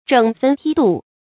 整纷剔蠹 zhěng fēn tī dù
整纷剔蠹发音